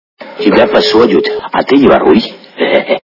» Звуки » Из фильмов и телепередач » Берегись автомобиля - Тебя посодют а ты не воруй
При прослушивании Берегись автомобиля - Тебя посодют а ты не воруй качество понижено и присутствуют гудки.